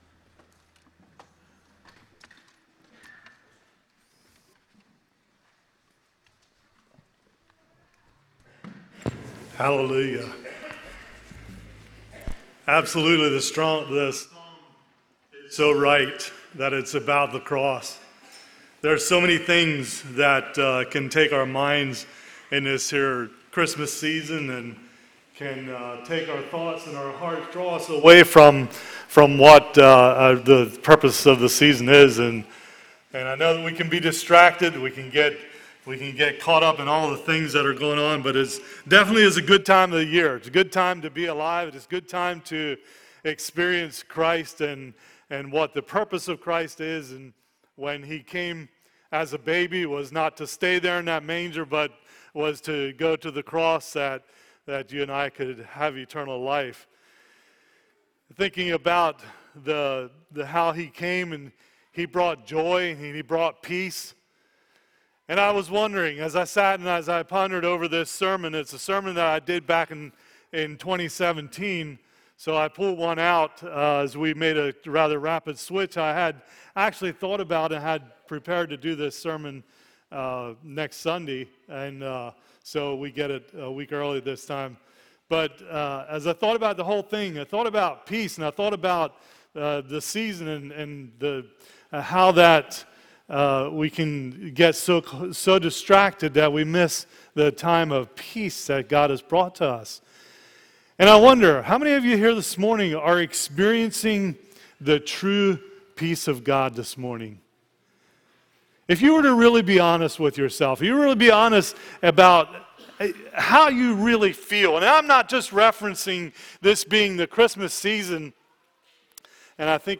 A message from the series "Sunday Morning - 10:30." Focus Scripture Psalms 46:1-11